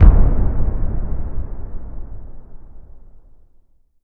LC IMP SLAM 7.WAV